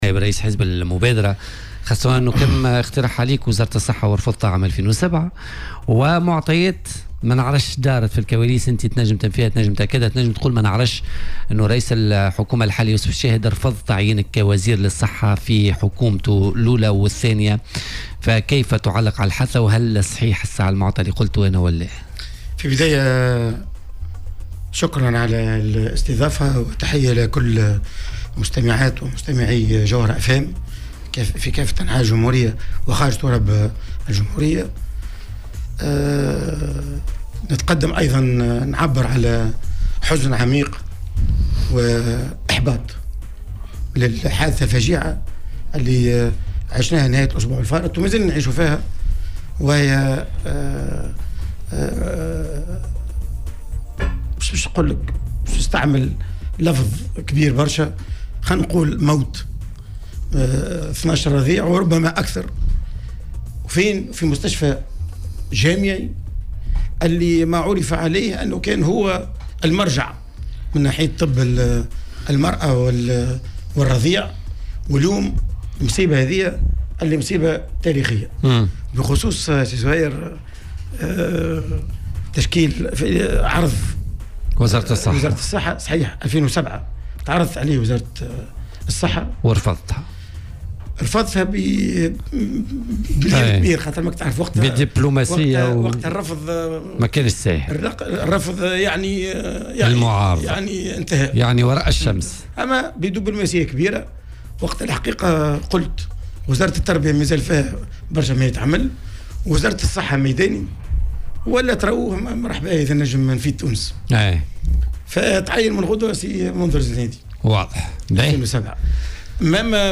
وقال ضيف "بوليتيكا" على "الجوهرة أف أم" إن الركائز الأساسية للمرافق العامة بصدد التدهور في تونس وخاصة في قطاعات التعليم والنقل والصحة، منبّها إلى تردّي مناخ العمل الذي أصبحت عليه المستشفيات.